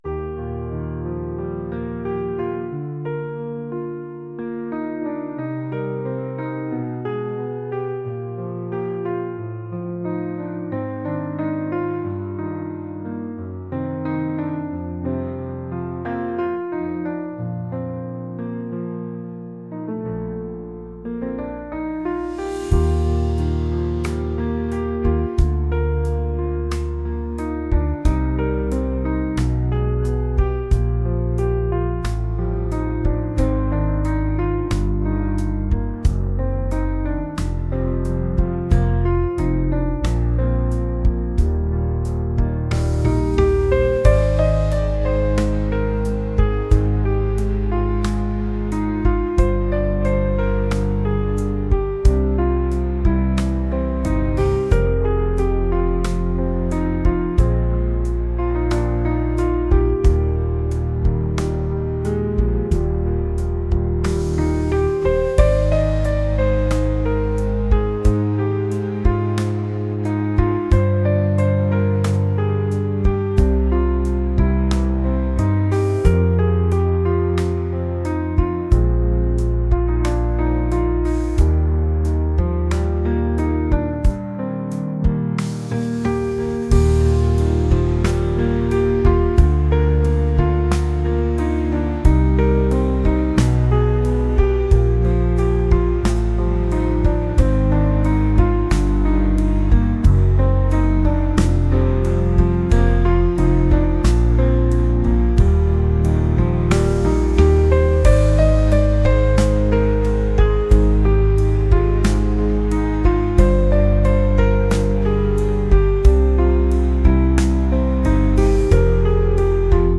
pop | retro | soulful